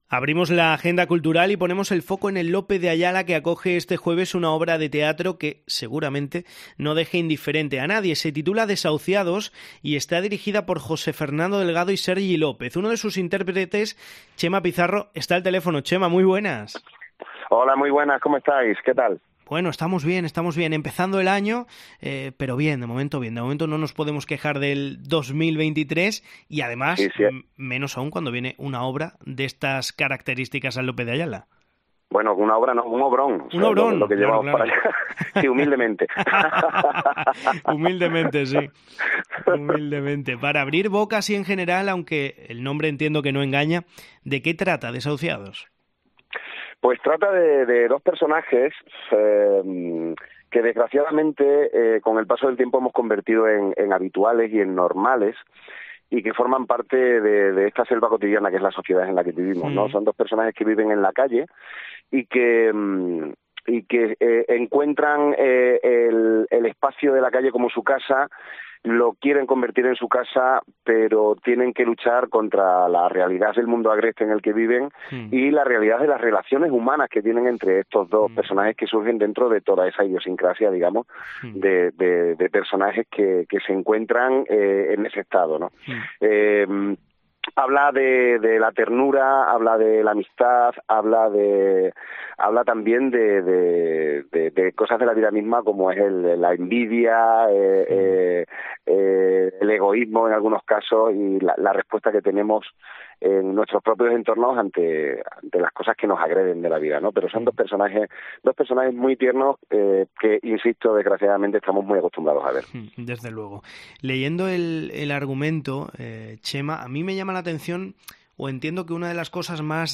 La obra, con aromas a Chaplin y Keaton, es el plato estrella del teatro esta semana. Charlamos con uno de sus intérpretes